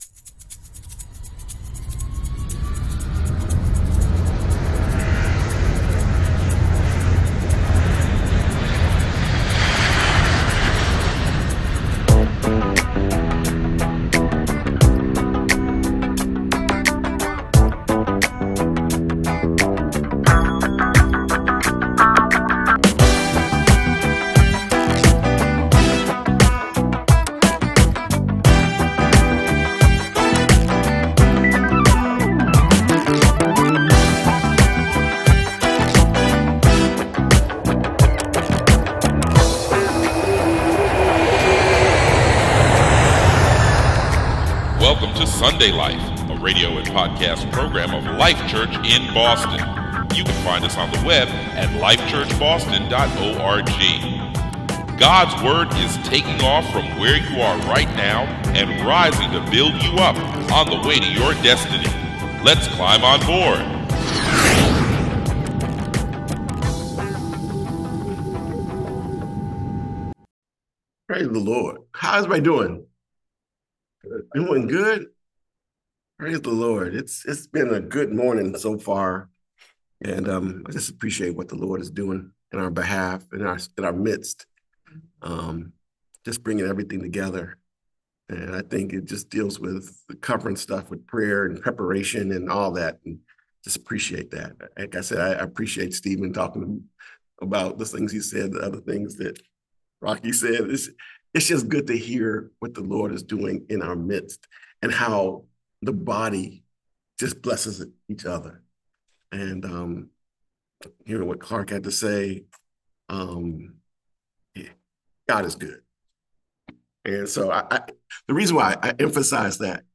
Notes for 06/29/2025 Sermon - Life Church Boston